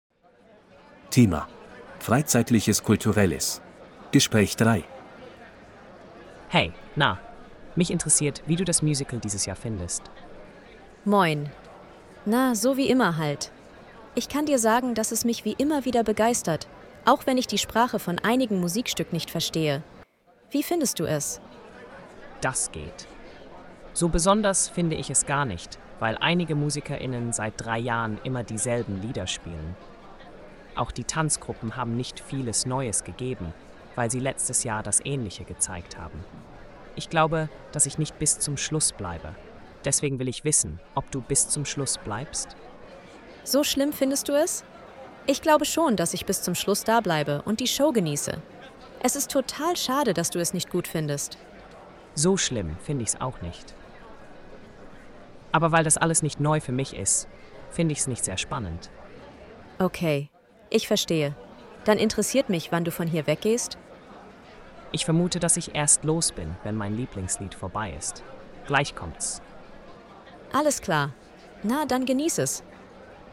Audio text conversation 3:
A2-Kostenlose-R-Uebungssatz-3-Freizeitliches-Kulturelles-Gespraech-3.mp3